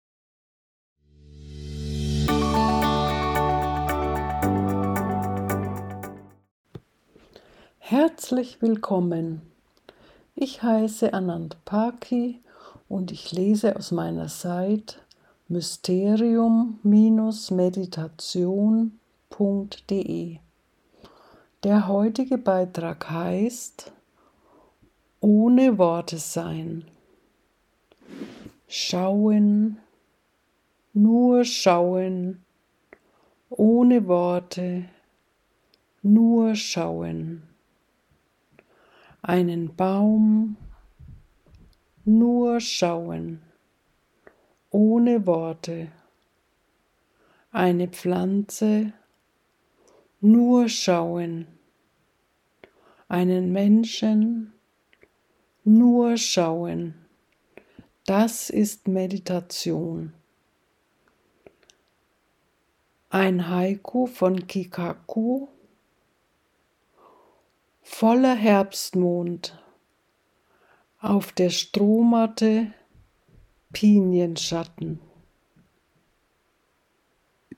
Eine Meditation des Schauens ohne zu denken. Mystik im Alltag.